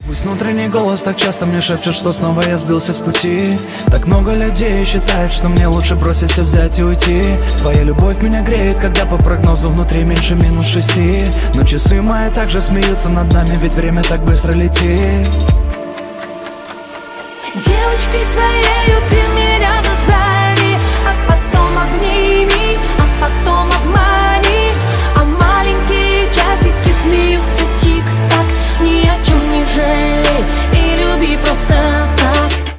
• Качество: 128, Stereo
Хип-хоп
тиканье часов